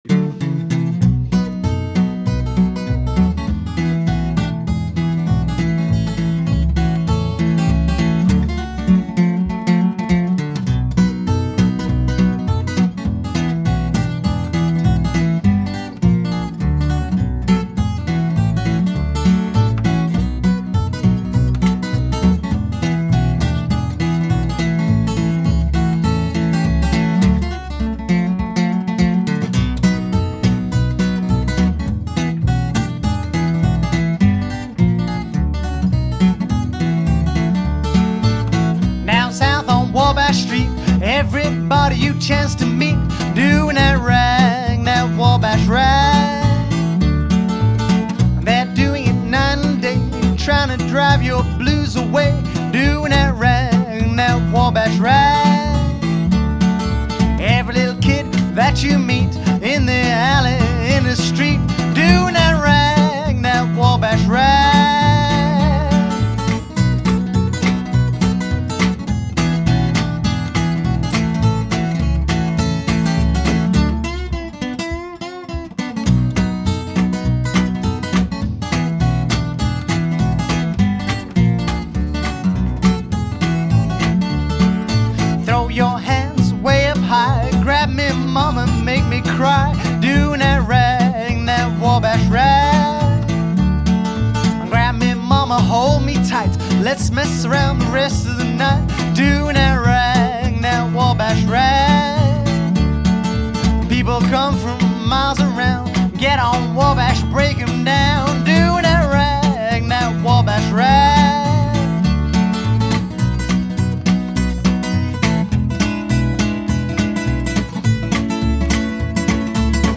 1920s & 30s Jazz, Hillbilly and Blues